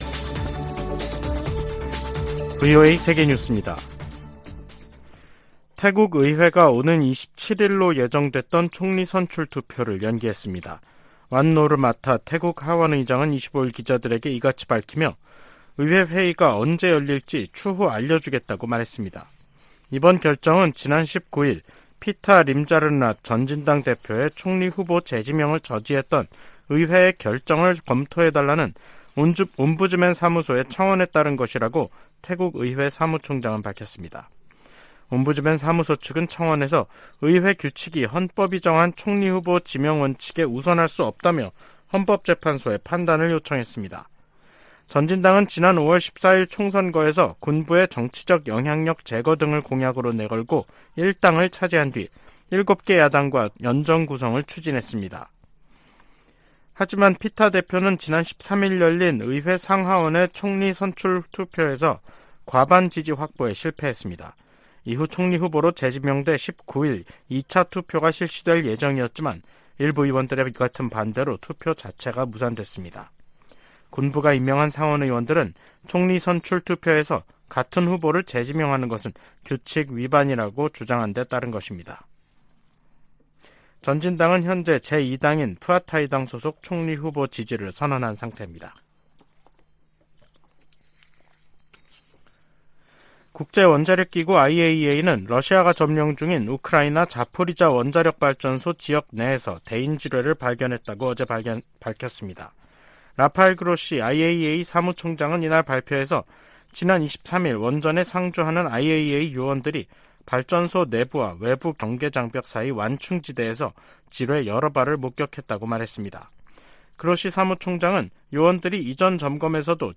VOA 한국어 간판 뉴스 프로그램 '뉴스 투데이', 2023년 7월 25일 3부 방송입니다. 백악관과 미 국무부는 월북 미군과 관련해 여전히 북한의 응답을 기다리는 중이며, 병사의 안위와 월북 동기 등을 계속 조사하고 있다고 밝혔습니다. 북한이 24일 탄도미사일 2발을 동해상으로 발사했습니다.